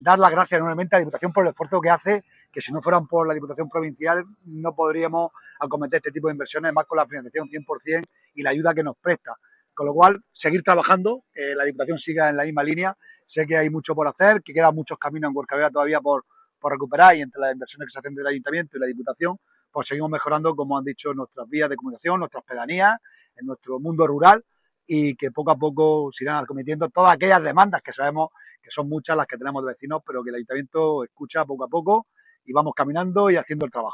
24-09_huercal_overa_obras_alcalde.mp3